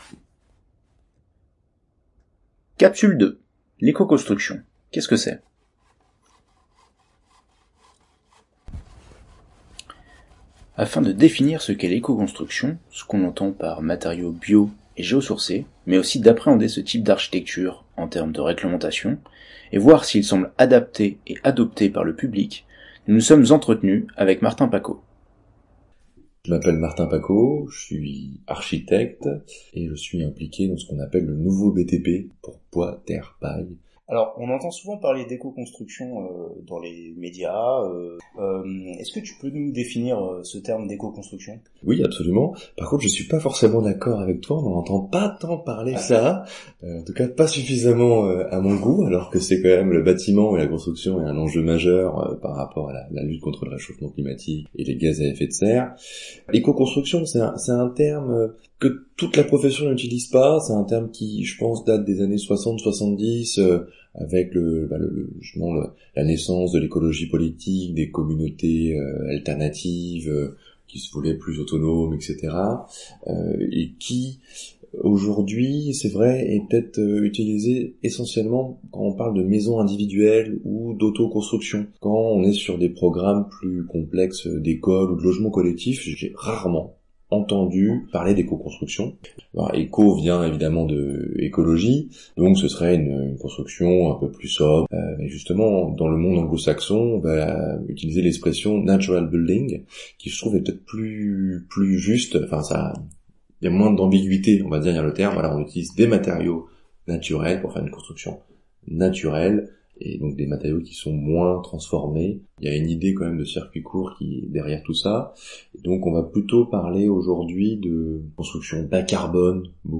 Il a été utilisé dans le cadre d'un cours universitaire et est mis à disposition du grand public via Canal U.